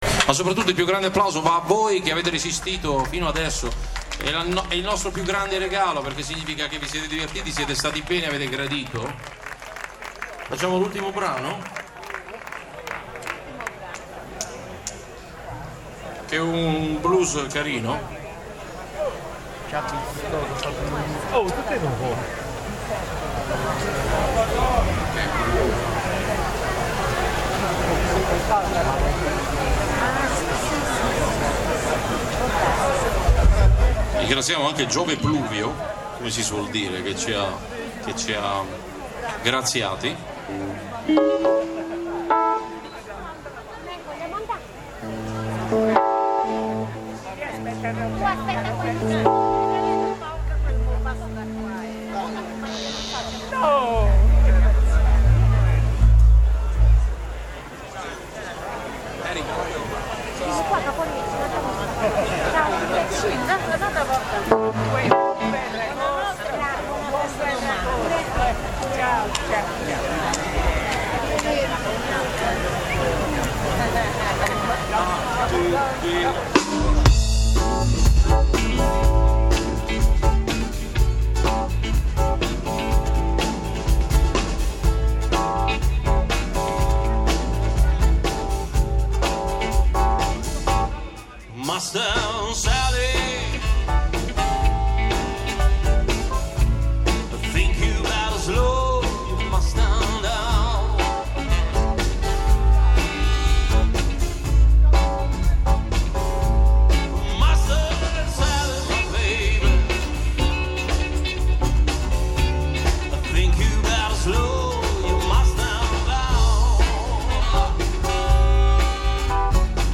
Di malavoglia siamo andati in piazza Riccardo ed avremmo fatto meglio ad anticipare i tempi, visto ciò che in circa tre o quattrocento stavano godendosi.
che con la batteria fa miracoli. Per dare una prova tangibile di ciò che dico, cliccate ed ascoltate il brano con il quale i Nostri hanno chiusa la festa.